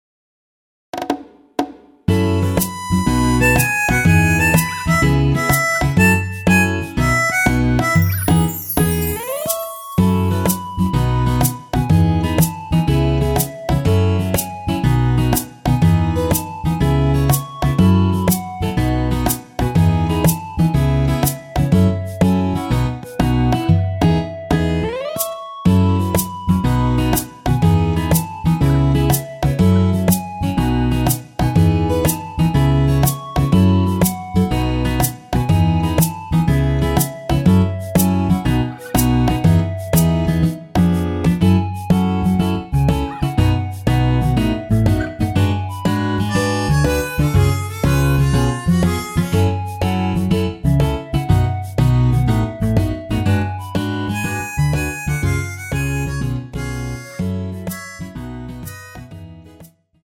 원키에서(+5)올린 멜로디 포함된 MR입니다.
멜로디 MR이라고 합니다.
앞부분30초, 뒷부분30초씩 편집해서 올려 드리고 있습니다.